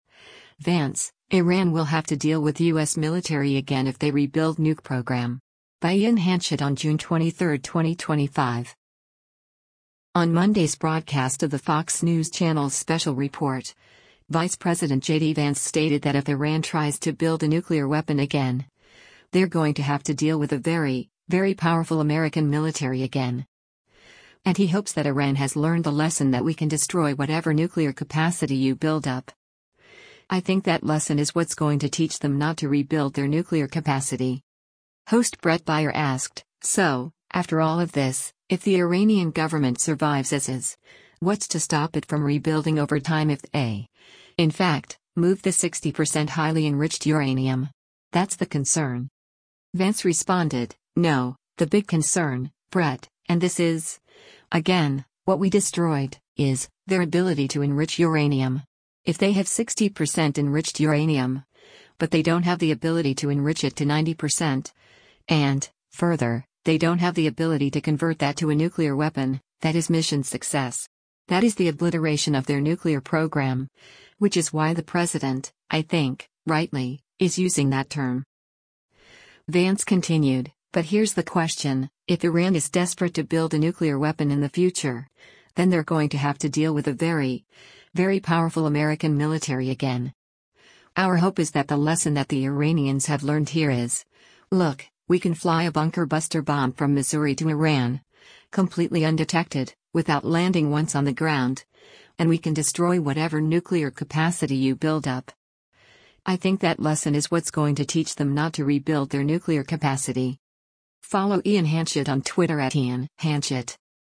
Host Bret Baier asked, “So, after all of this, if the Iranian government survives as is, what’s to stop it from rebuilding over time if they, in fact, moved the 60% highly enriched uranium? That’s the concern.”